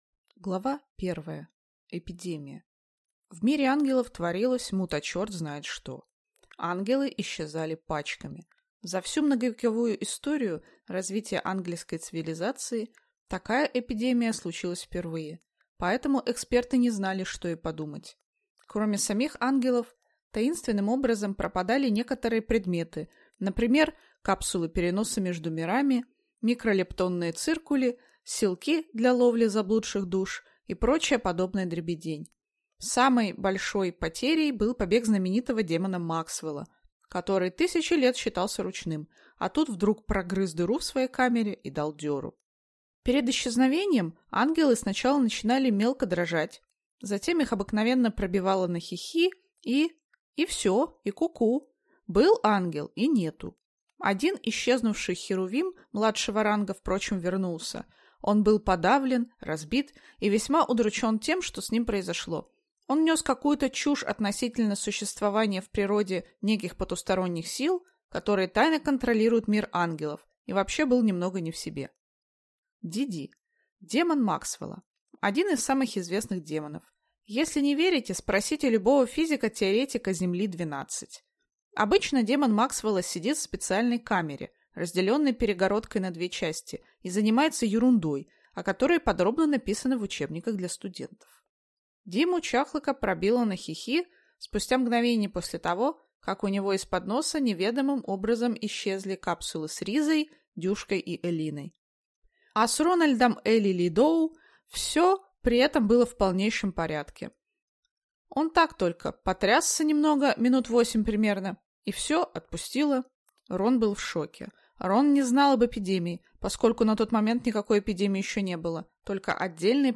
Аудиокнига Уровень дзета | Библиотека аудиокниг
Прослушать и бесплатно скачать фрагмент аудиокниги